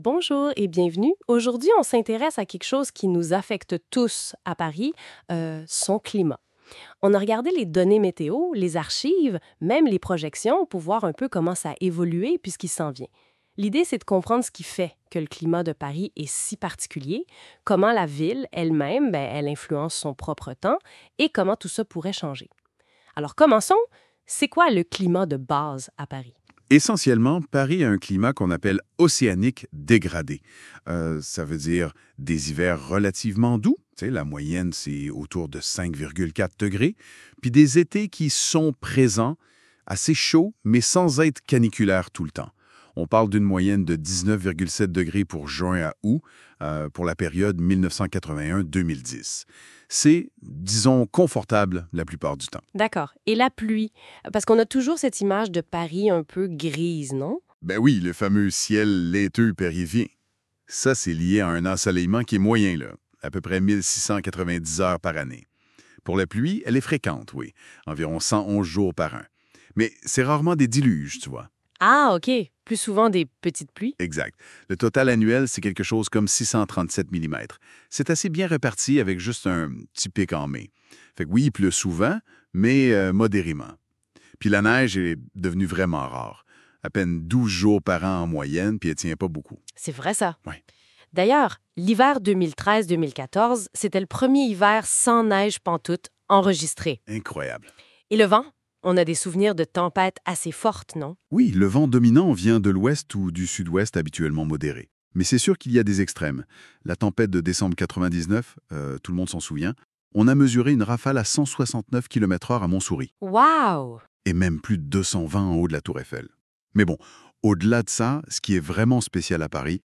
Québécois